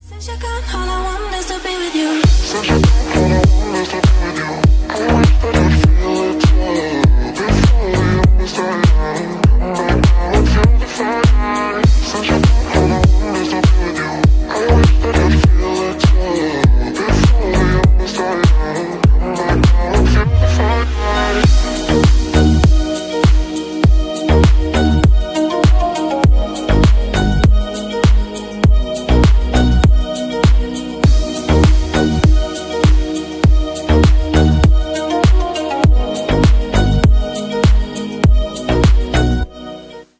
Танцевальные
восточные